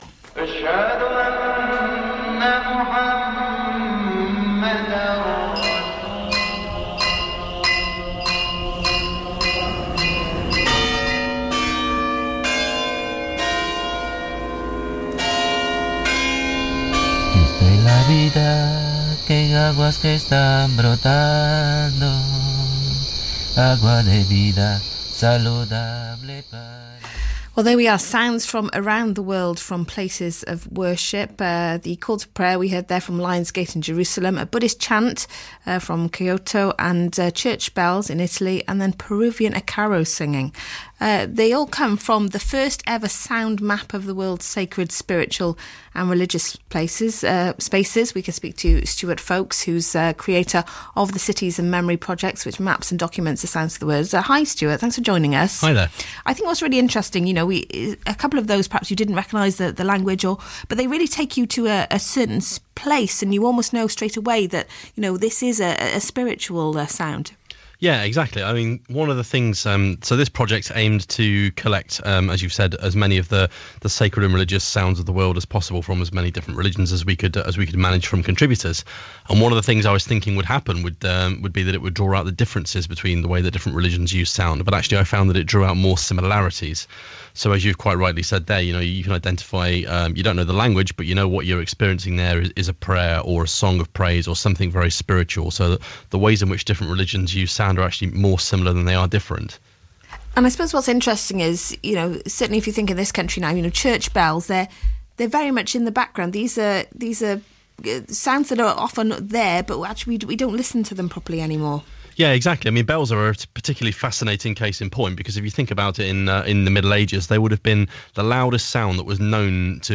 BBC Radio Wales - Sacred Spaces interview